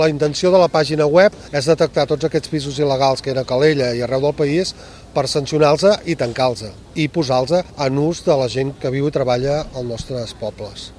Ho ha explicat el diputat Dani Cornellà en roda de premsa, aquest migdia a la Plaça del Bunyol.